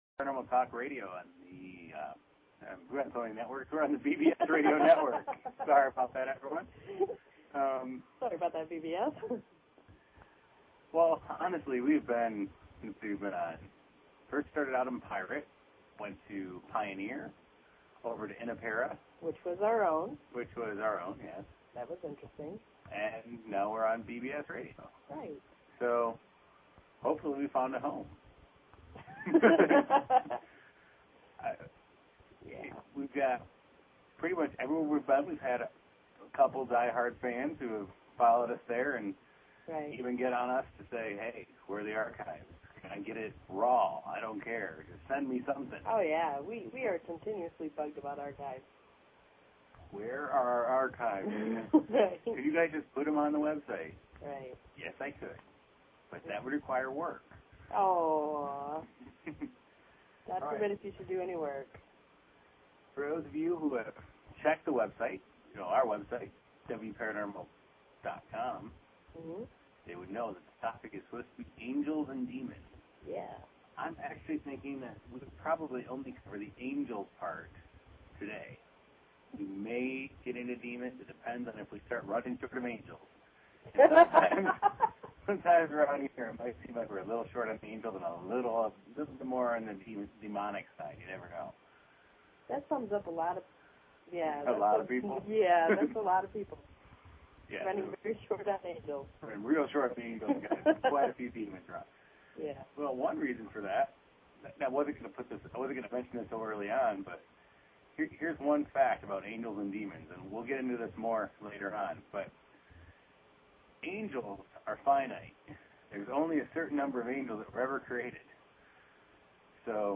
Talk Show Episode, Audio Podcast, Wparanormal and Courtesy of BBS Radio on , show guests , about , categorized as